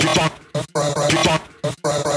这款采样包包含 62 个极具感染力的人声采样，是您为音乐注入原始能量的理想之选。